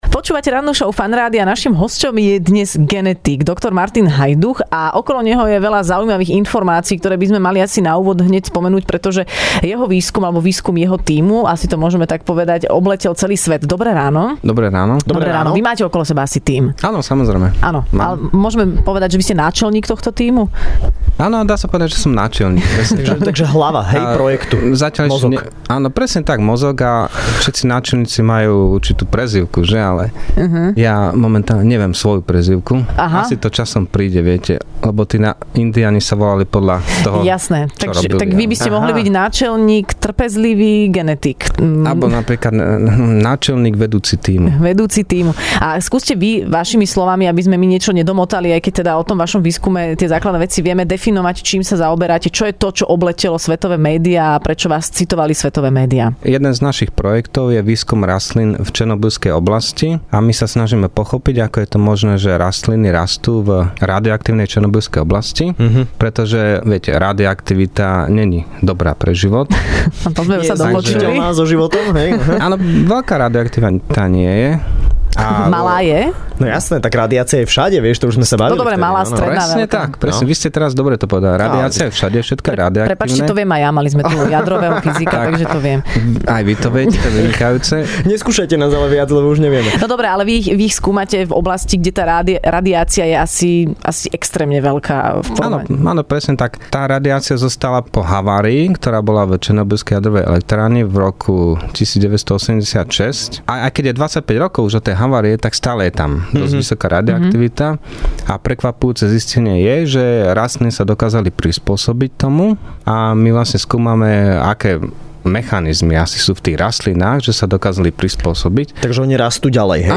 Hosťom v Rannej šou bol genetik